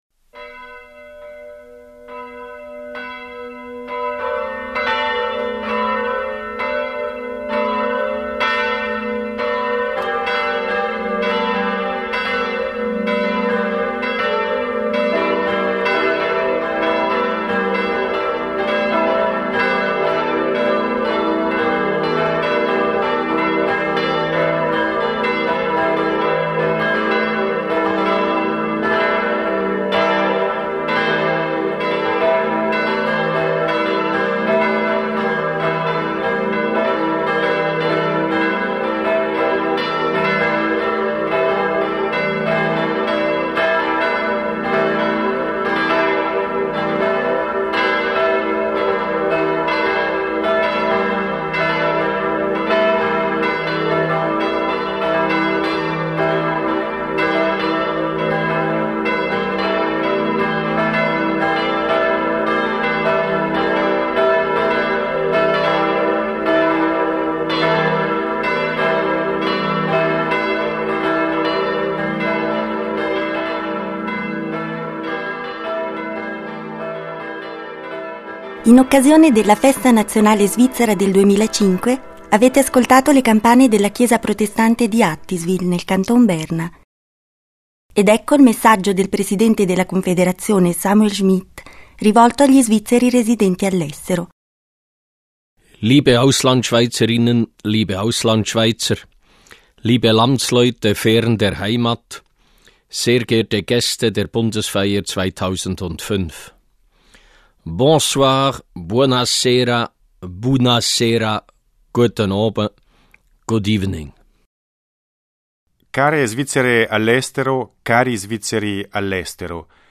Messaggio del presidente della Confederazione Samuel Schmid agli Svizzeri all’estero in occasione della Festa nazionale.